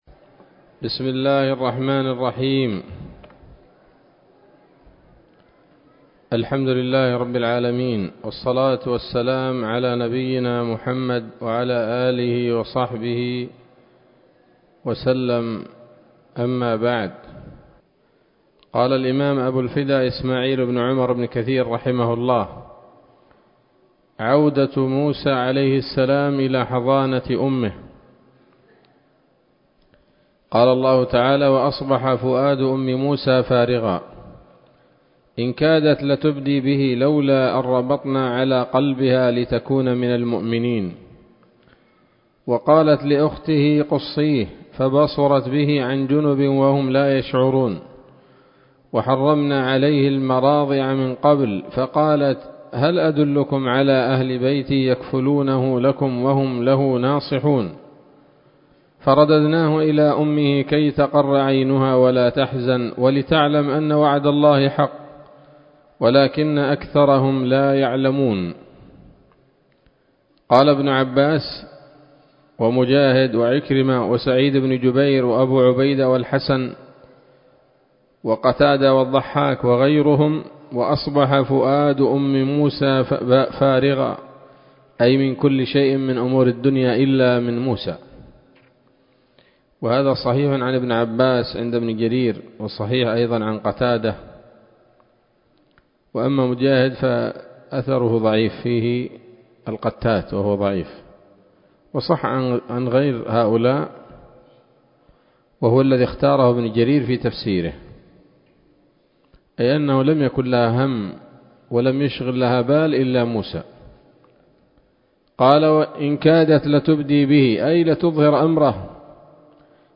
الدرس الثاني والثمانون من قصص الأنبياء لابن كثير رحمه الله تعالى